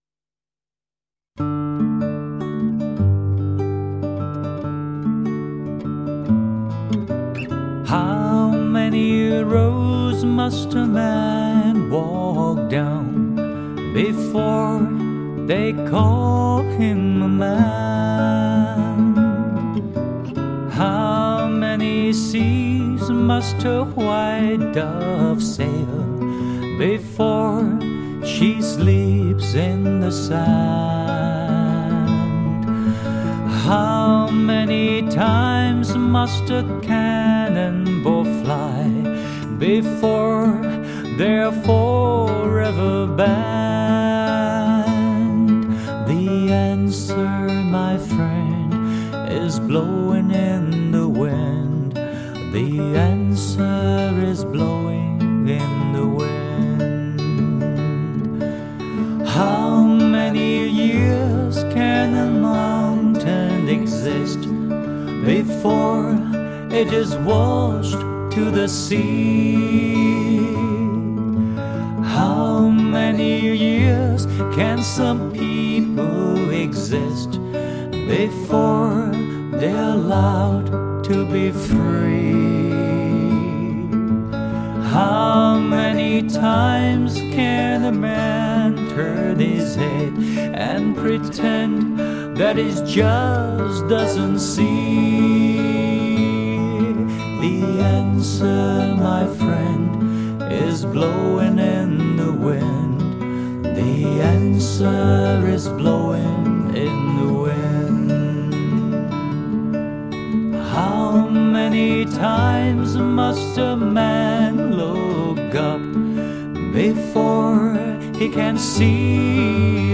简朴、清新的伴奏，恰到好处；浑厚、干净的声音，是那么完美，有的甚至比原唱更精致。